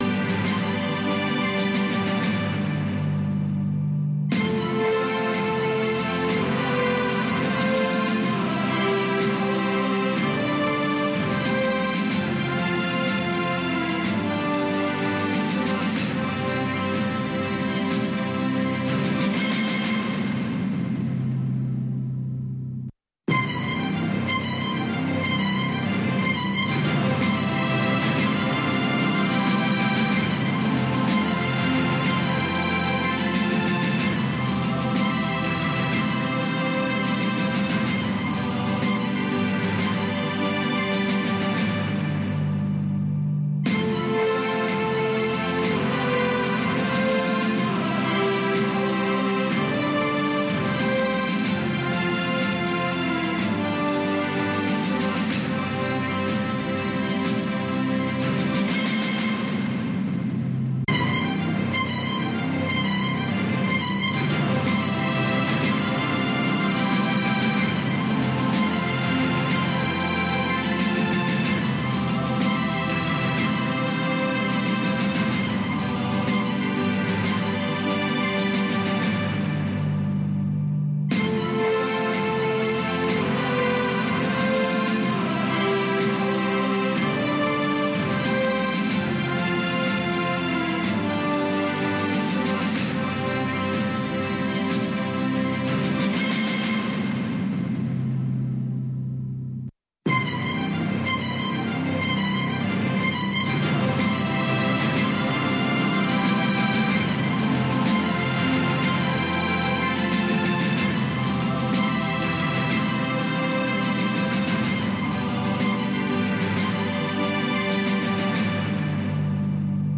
خبرونه